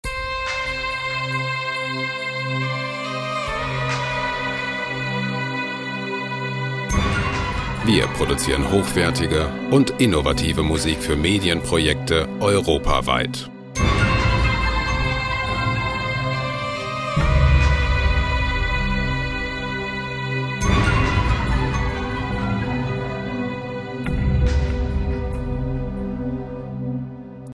epische Musikloops lizenzfrei
Musikstil: Soundtrack
Tempo: 70 bpm